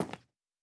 added stepping sounds
Tile_Mono_02.wav